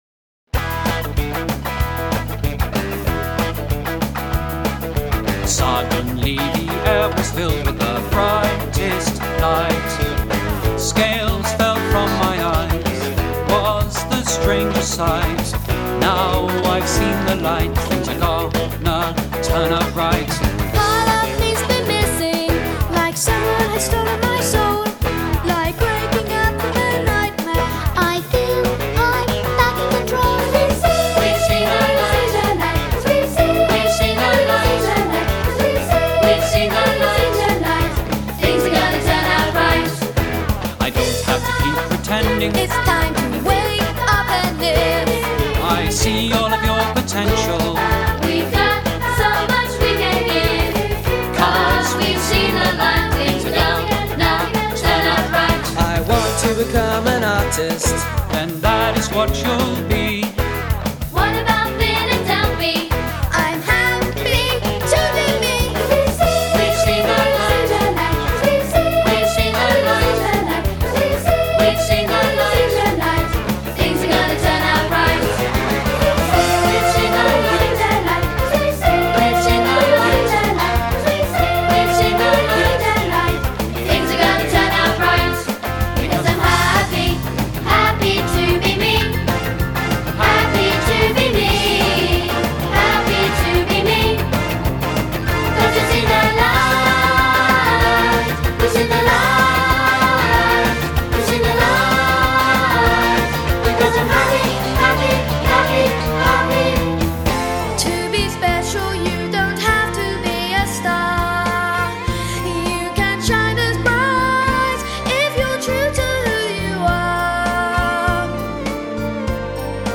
They all sing:
Souls intact and wiser at last, they segue SEEN THE LIGHT into an upbeat version of Finn’s HAPPY TO BE ME.